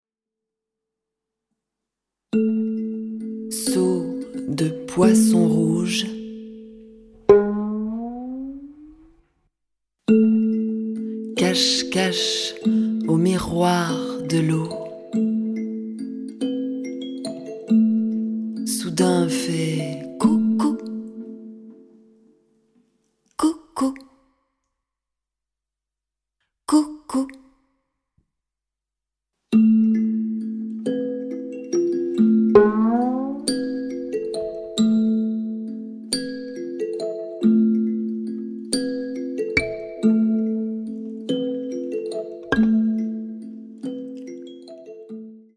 Lus, chantés, accompagnés à la guitare ou au violon